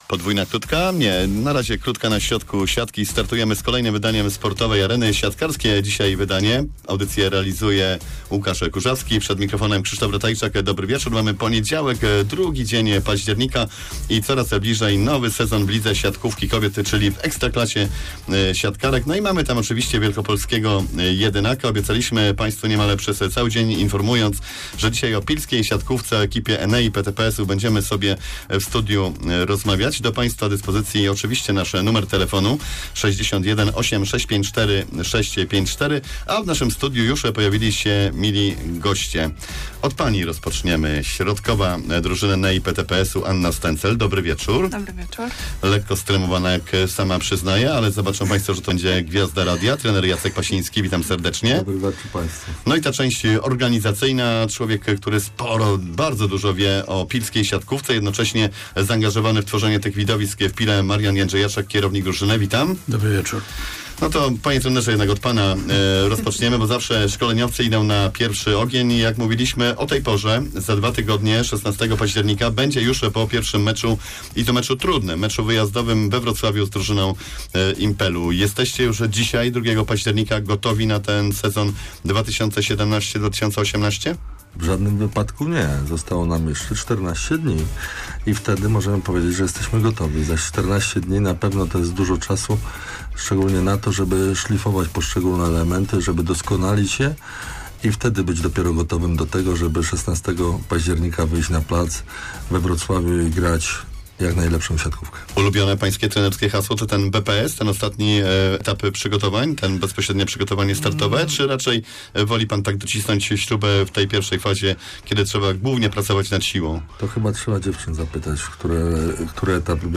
Przedstawiciele grającego w Ekstraklasie kobiet klubu Enea PTPS Piła odwiedzili studio Radia Poznań.